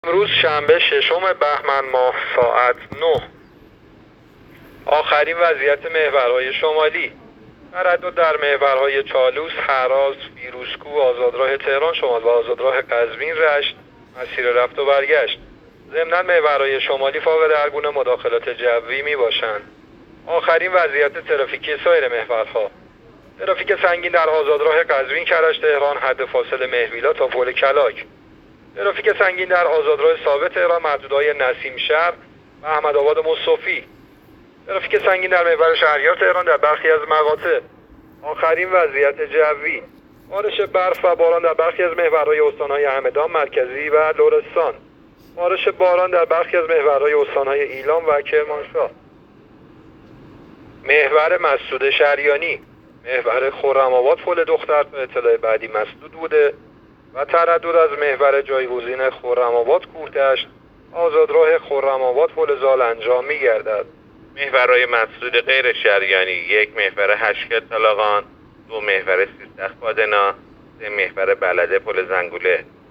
گزارش رادیو اینترنتی از آخرین وضعیت ترافیکی جاده‌ها ساعت ۹ ششم بهمن؛